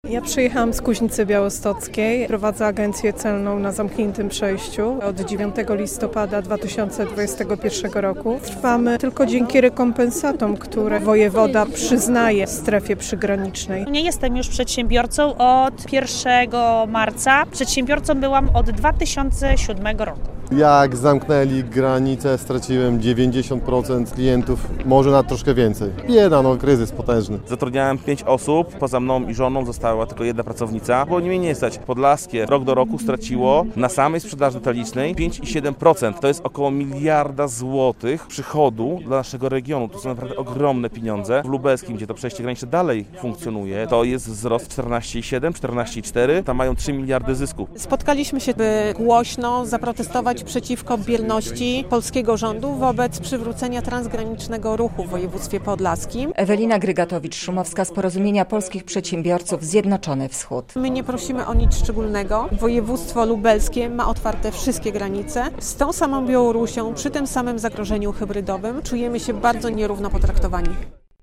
Podlascy przedsiębiorcy protestowali w związku z zamknięciem przejść granicznych - relacja